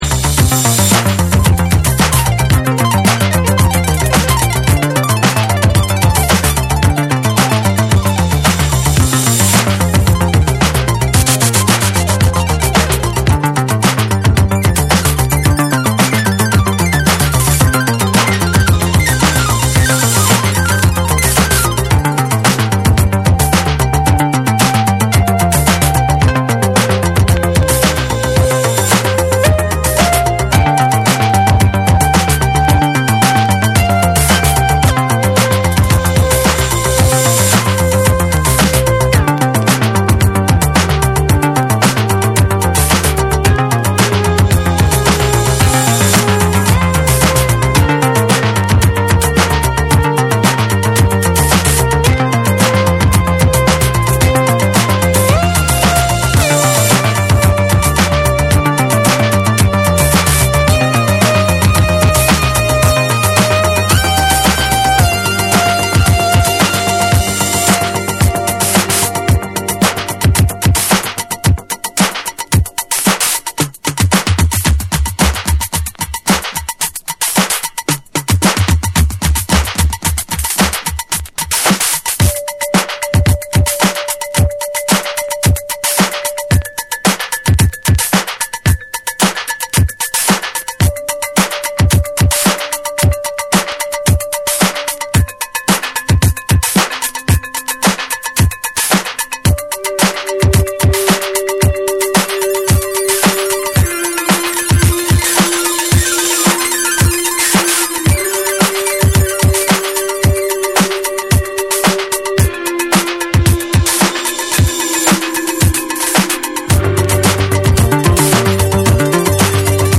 DANCE CLASSICS / DISCO / RE-EDIT / MASH UP